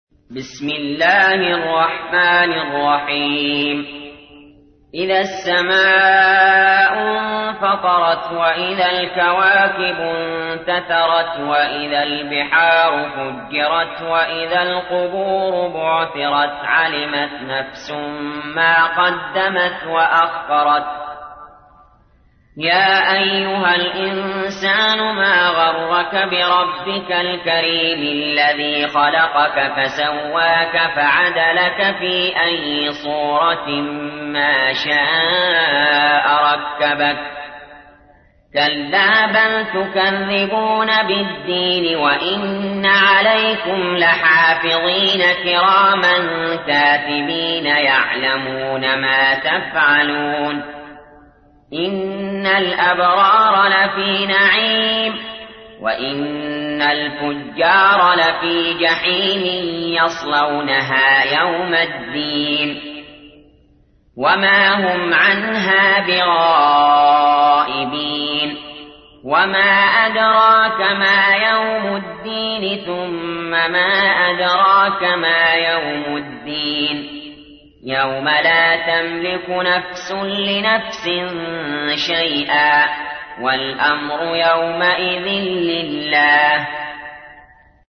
تحميل : 82. سورة الانفطار / القارئ علي جابر / القرآن الكريم / موقع يا حسين